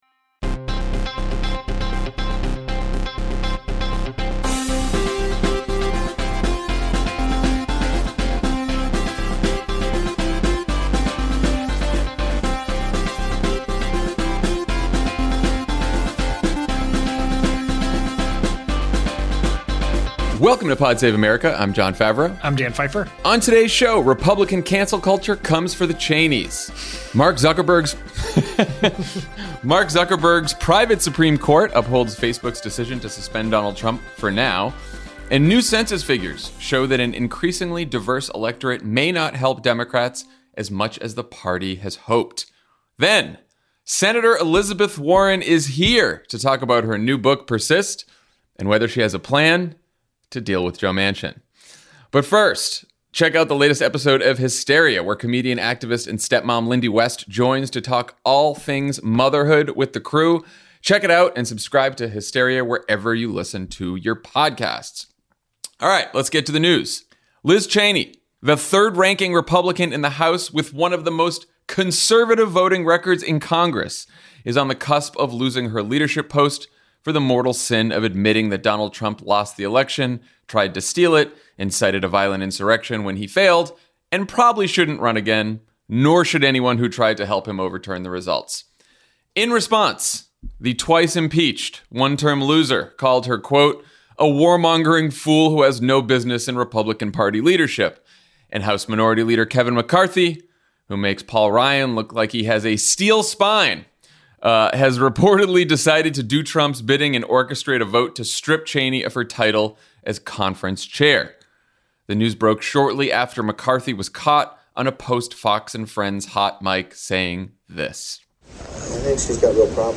Republican cancel culture comes for the Cheneys, Mark Zuckerberg’s private Supreme Court upholds Facebook’s decision to suspend Donald Trump, and new Census figures show that an increasingly diverse electorate may not help Democrats as much as the party has hoped. Then, Senator Elizabeth Warren talks to Jon Favreau about her new book, Persist, and whether she has a plan to deal with Joe Manchin.